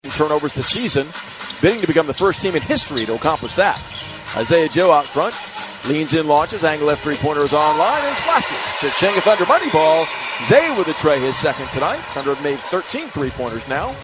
Thunder PBP 1-3.mp3